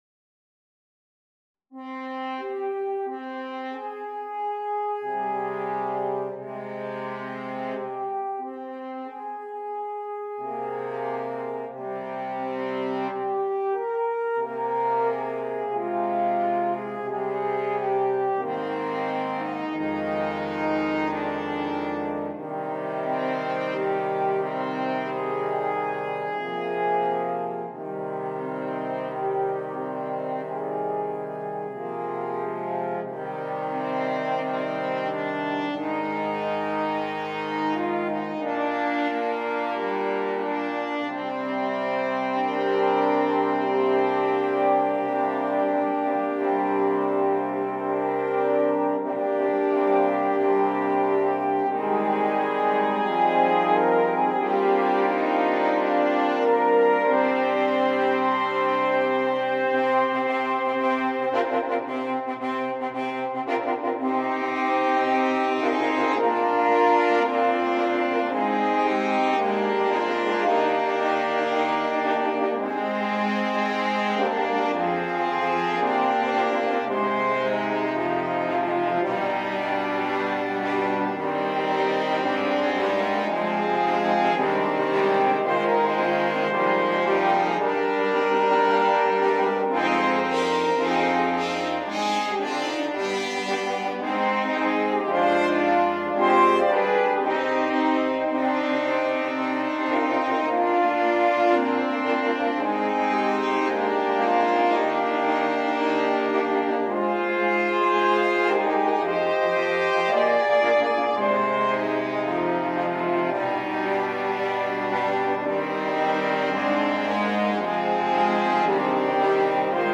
F Horn Choir…